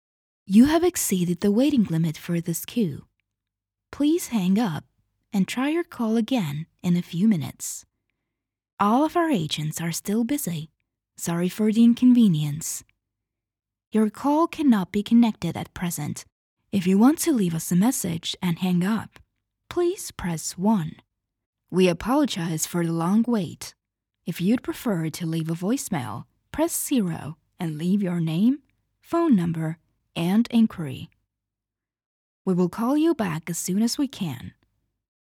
IVR
Soy locutora estonia nativa y trabajo tanto en estonio como en inglés, ¡con un ligero acento!
Mi acento es lo suficientemente suave como para ser fácilmente comprensible, además de ser adecuado para conectar con gente de todo el mundo.